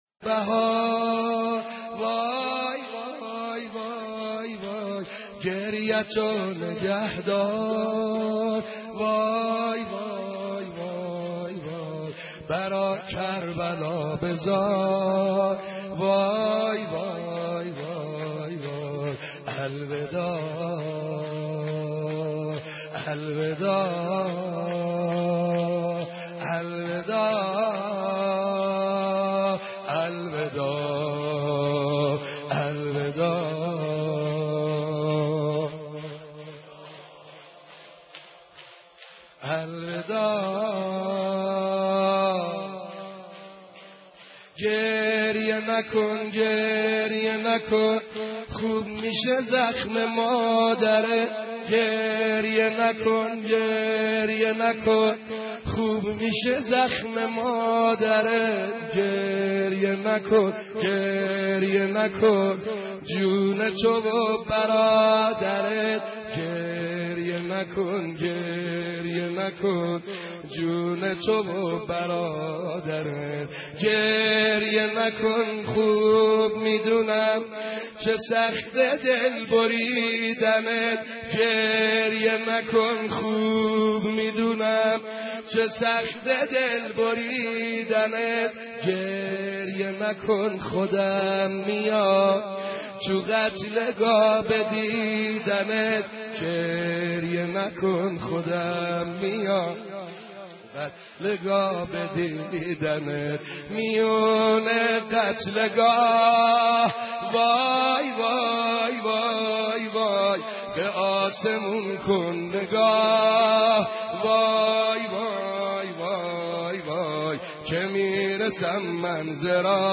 مراسم مداحی برای شهادت بانوی عالمین حضرت فاطمه(س) توسط حاج سعید حدادیان (4:26)